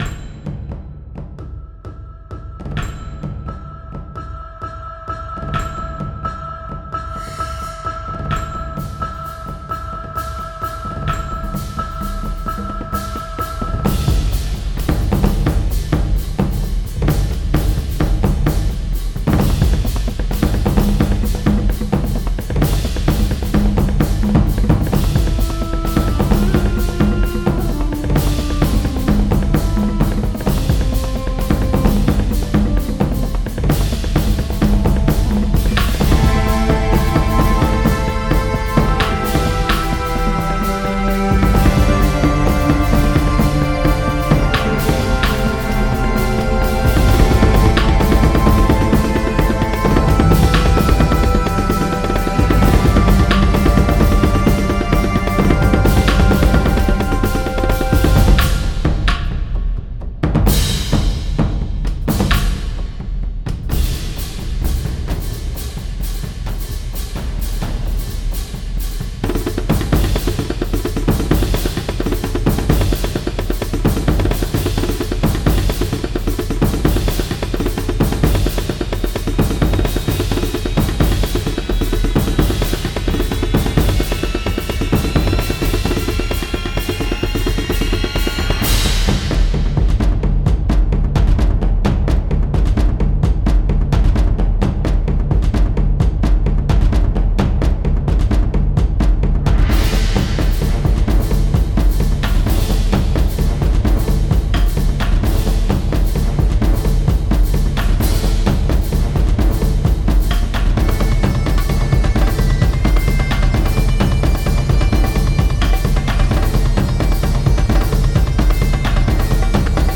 Fight Theme